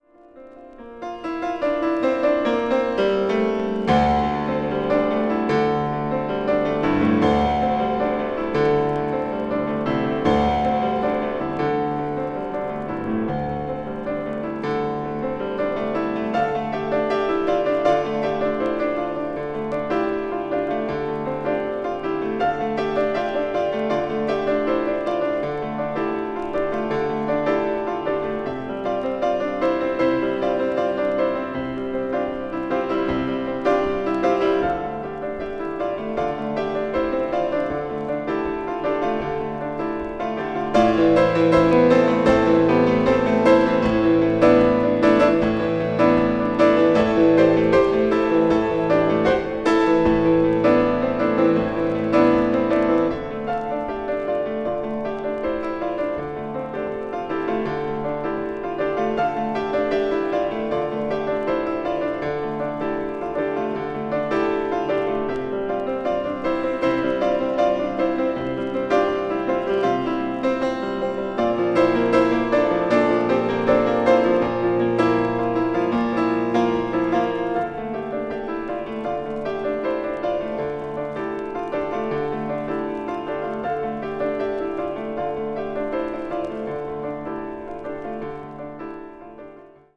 media : EX/EX(わずかにチリノイズが入る箇所あり)
同年2月にパルコ・シアターで行われたコンサートのライブ・レコーディング音源を収録。
ambient   new age   piano solo